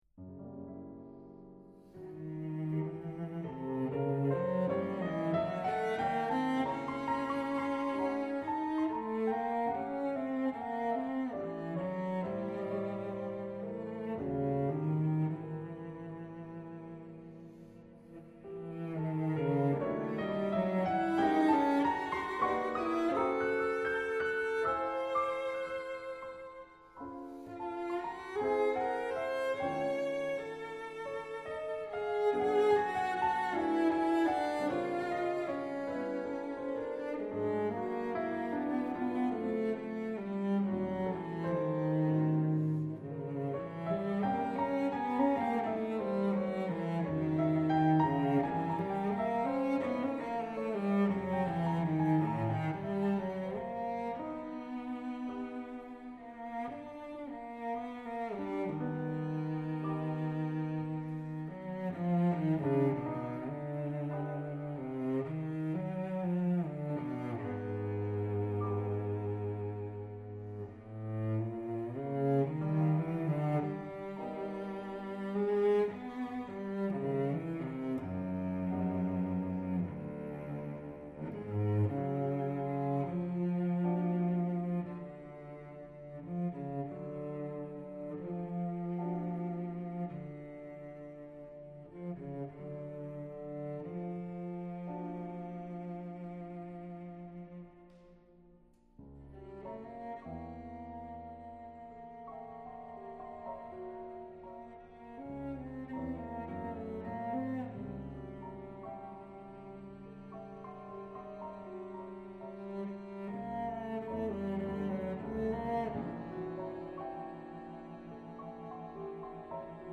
'cello and piano
highly energetic